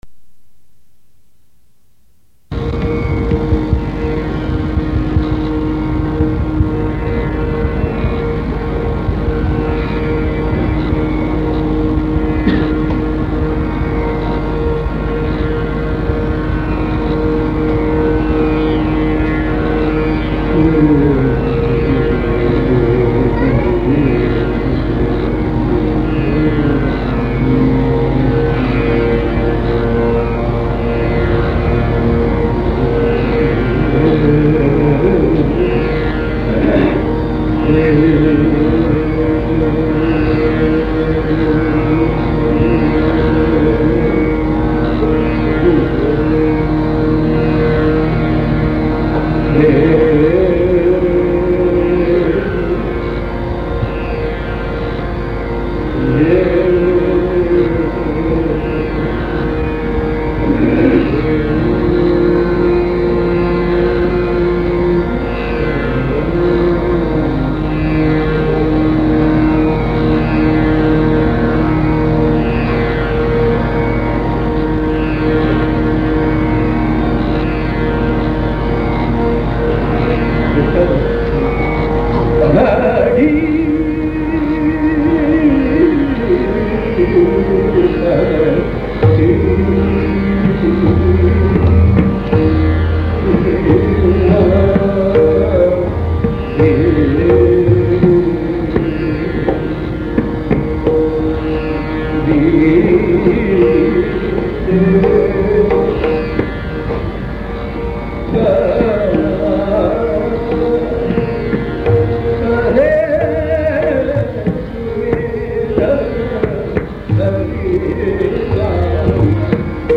Gaud Sarang and Megh.